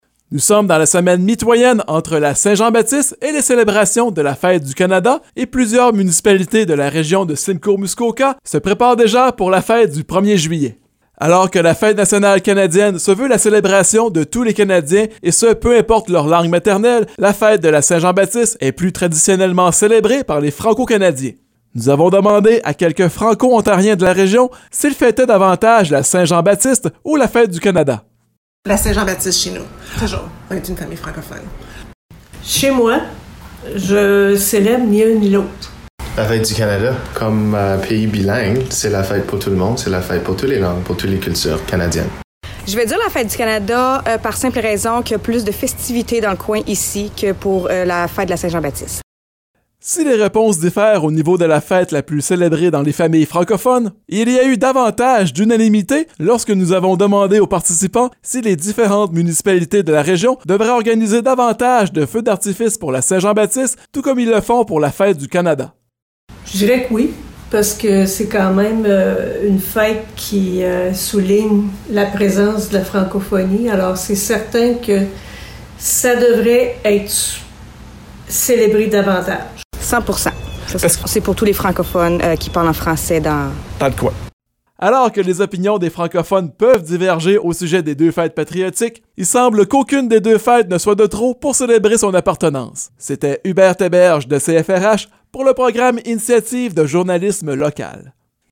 Nous avons demandé à des francophones de la région s’ils célébraient davantage la Saint-Jean-Baptiste ou la fête du Canada.